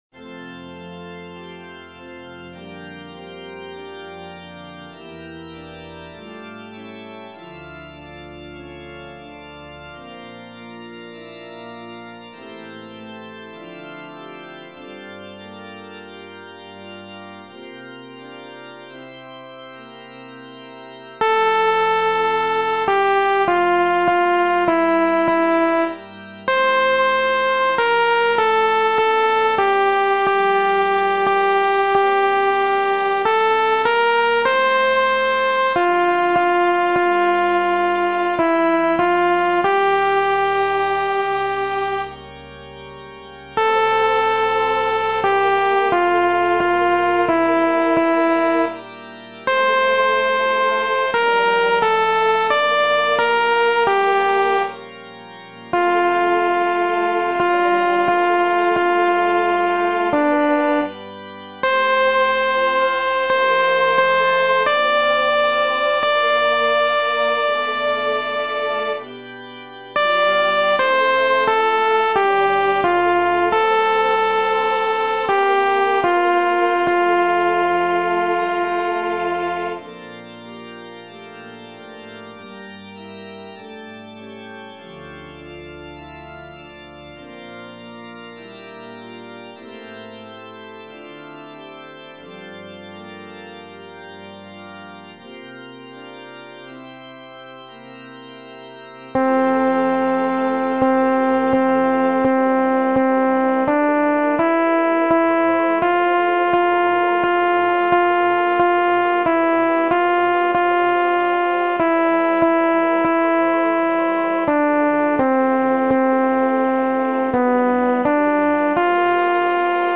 アルト1（フレットレスバス音）